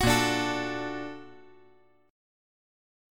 A#+M7 Chord
Listen to A#+M7 strummed